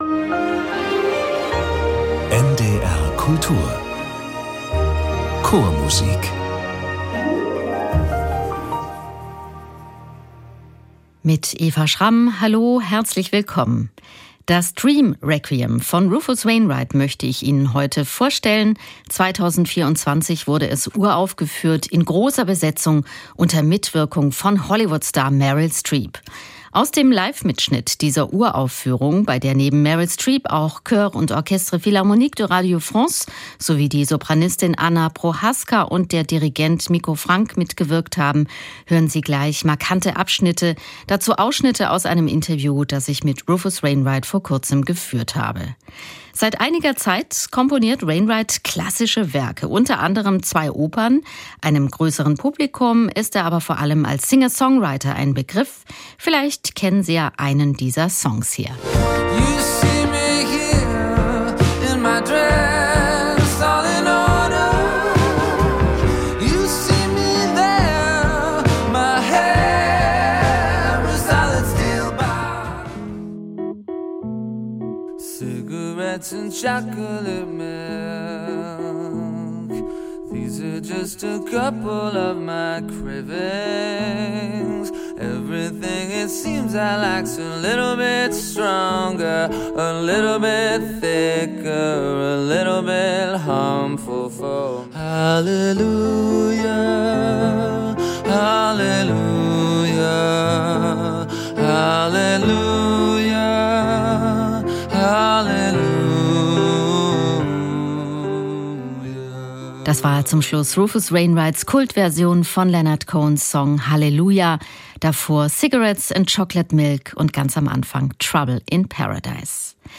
Einblicke in die Pariser Uraufführung 2024 mit Meryl Streep und einem persönlichen Gespräch mit dem Komponisten.